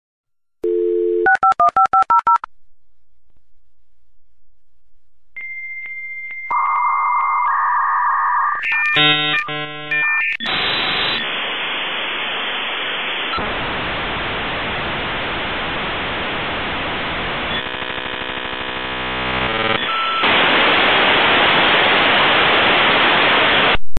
56k modem
56k-dialup-modem.mp3